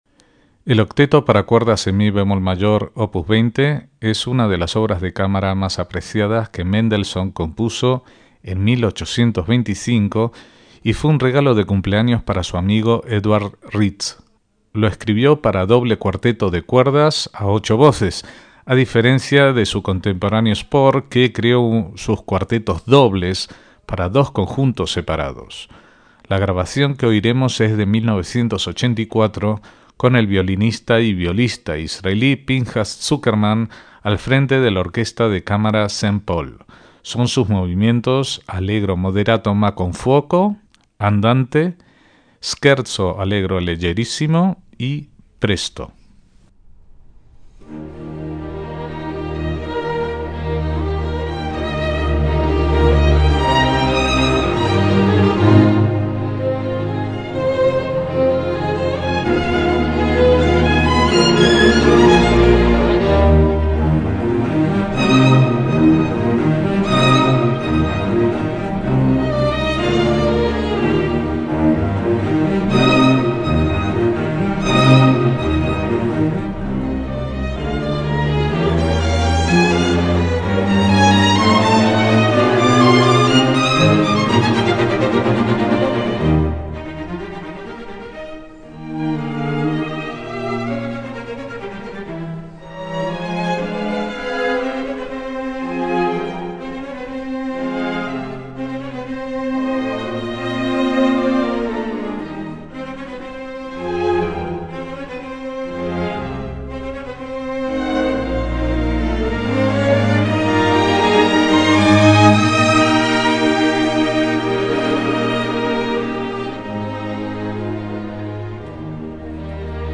MÚSICA CLÁSICA
en mi bemol mayor